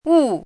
chinese-voice - 汉字语音库
wu4.mp3